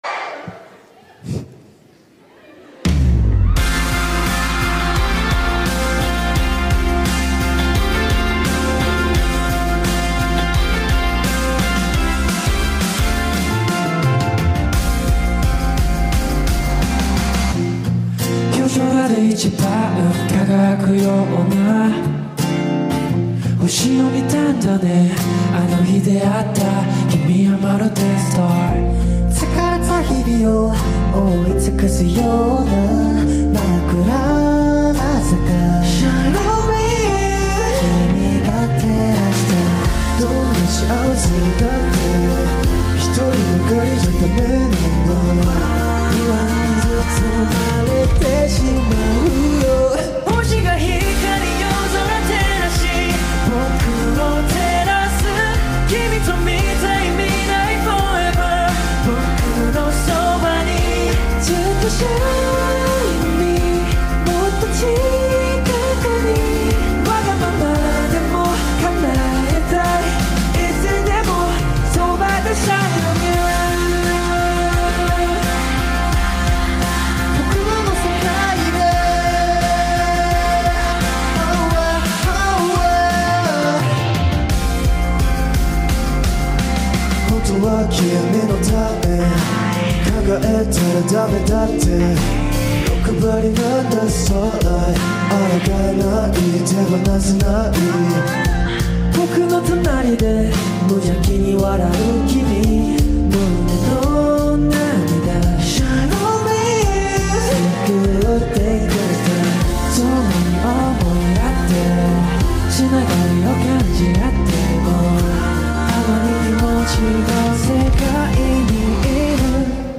Live at Japan Today on TikTok
HOLY VOCALS GUYS HELLO??